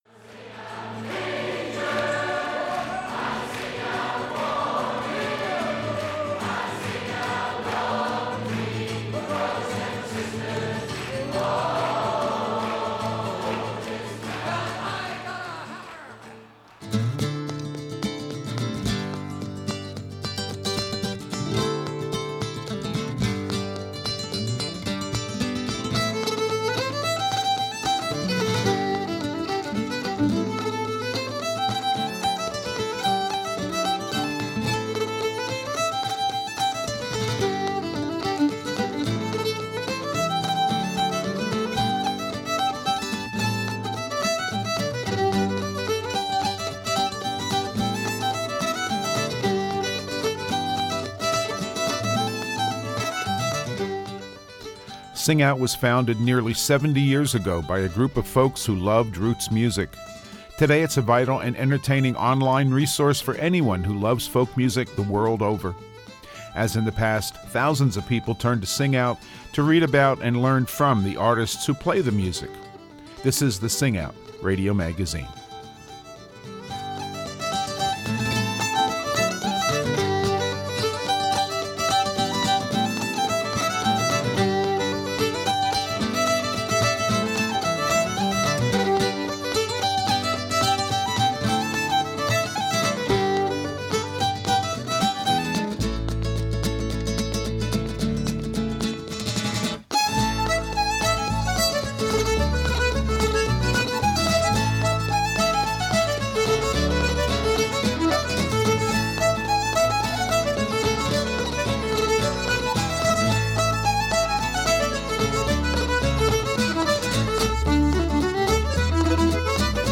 This week we continue to feature musicians who have been inspired by the music of their home region.